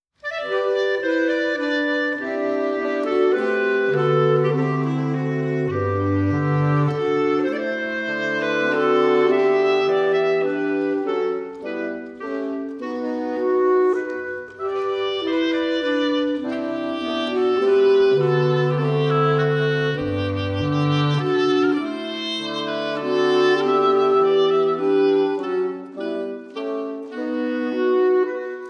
Rose Canyon Harmonie at Coos History Museum Plaza Stage
A unique reed quintet
bassoon
oboe
clarinet
saxophone
bass clarinet.
Here are some clips from the Rose Canyon Harmonie performance: